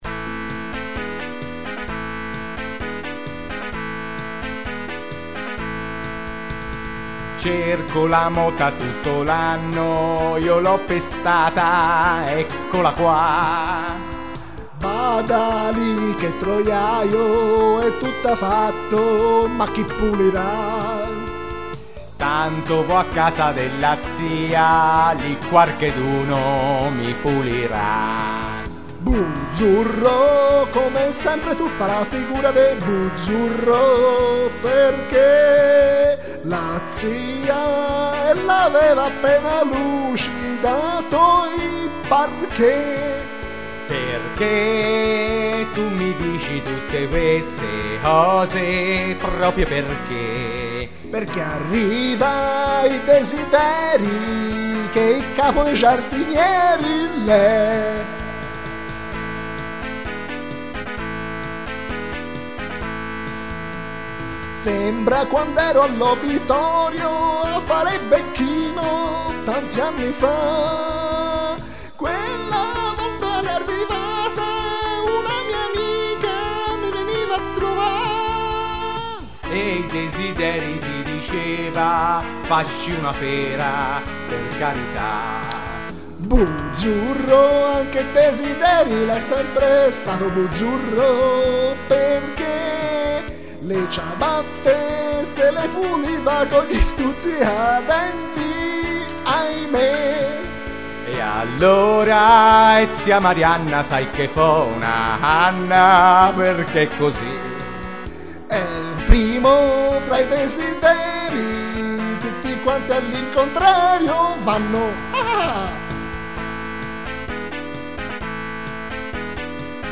rivisitata improvvisatamente